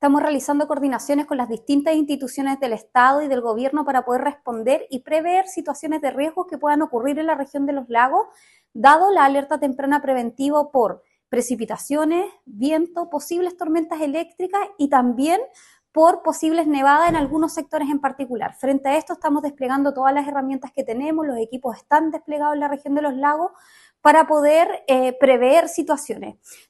La Delegada Presidencial Regional de Los Lagos, Giovanna Moreira, indicó que están realizando coordinaciones con las distintas instituciones del Estado para poder responder ante situaciones de riesgo.